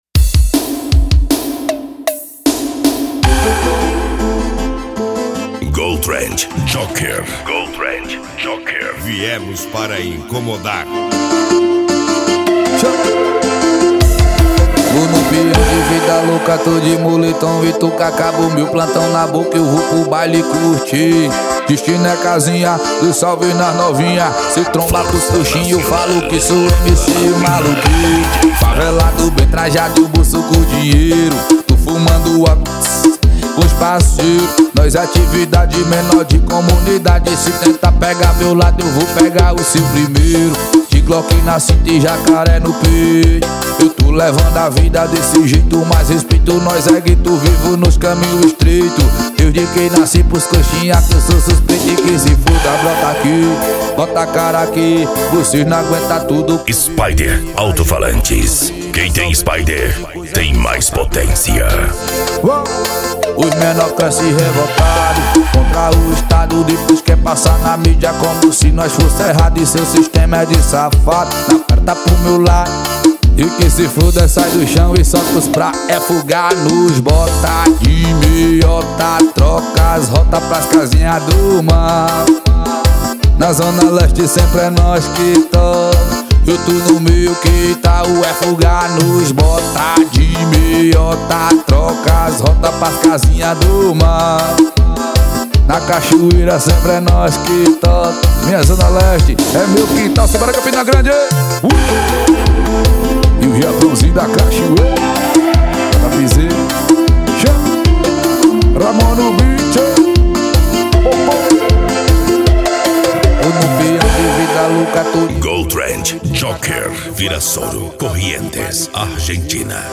Arrocha
Funk
Remix